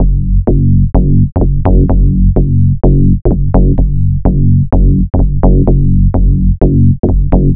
• Techno Rich Mid Bass.wav
Technoi_Rich_Mid_Bass__KT7.wav